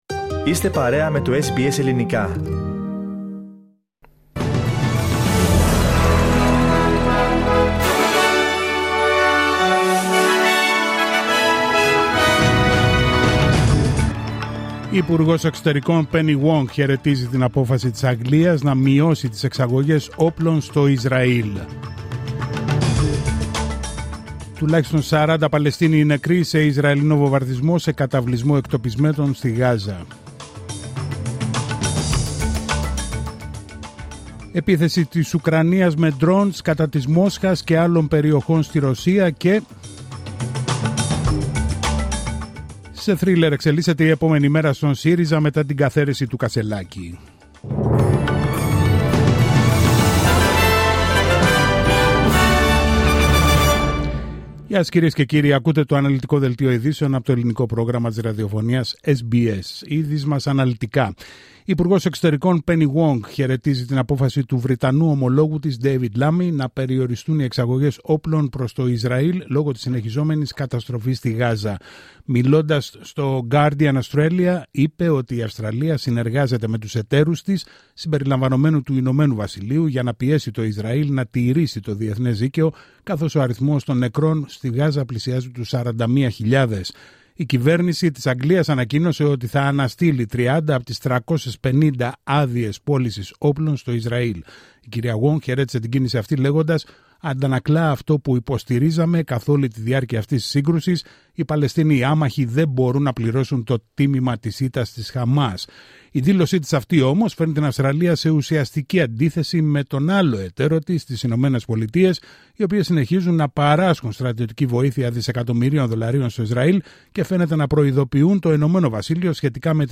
Δελτίο ειδήσεων τρίτη 10 Σεπτεμβρίου 2024